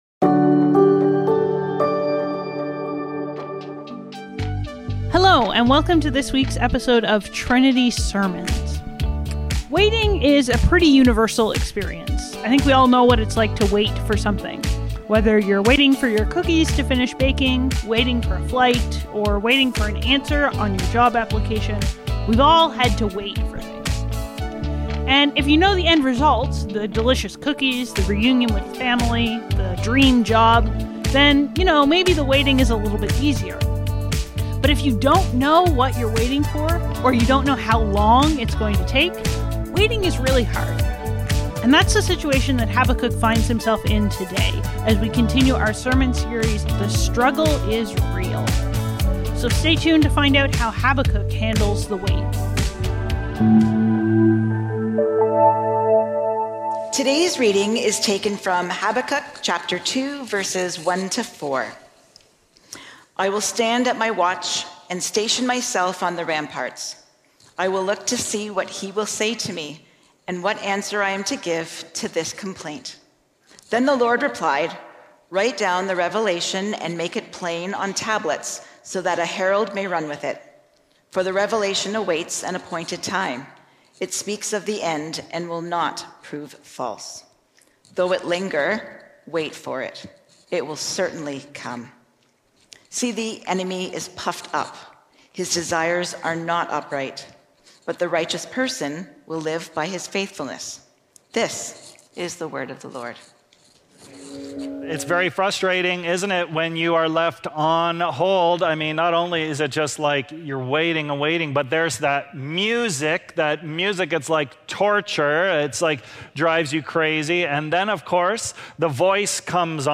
Trinity Streetsville - On Hold | The Struggle Is Real | Trinity Sermons